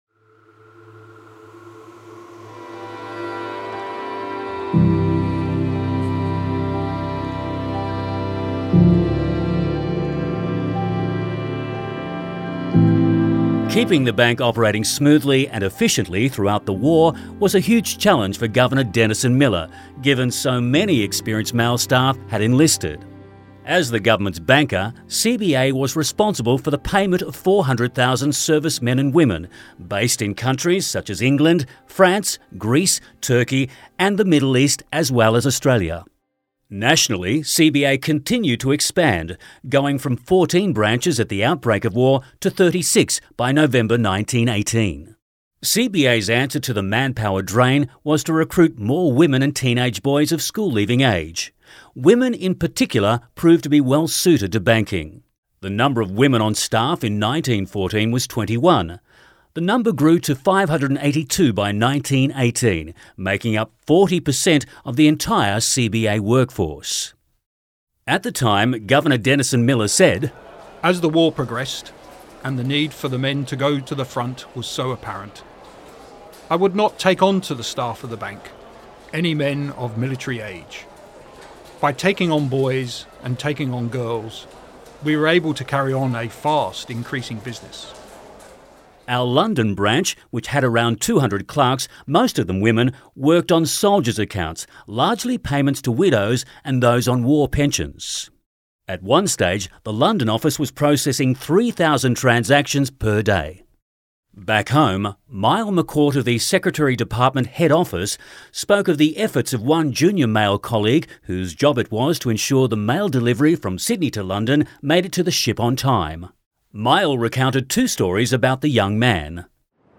• Narrator
• Additional voices